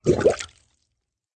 waterfilling.ogg